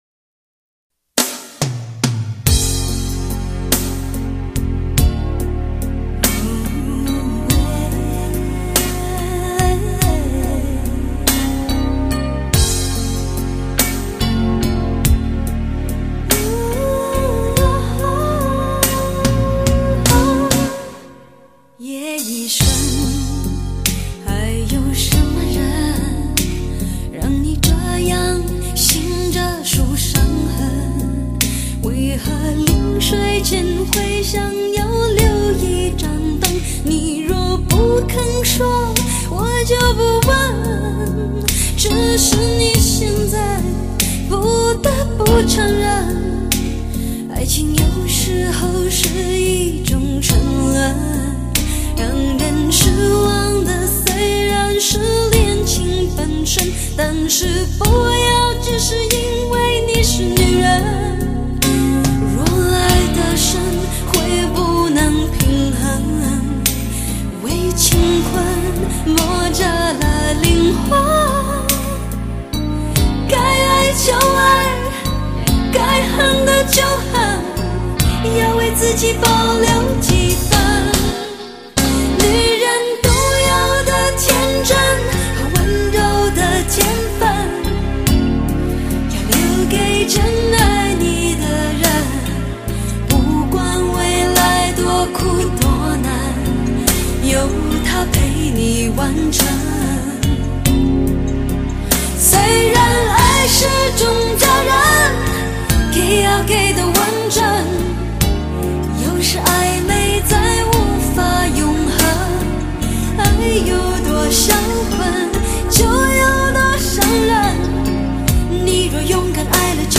类型: 流行经典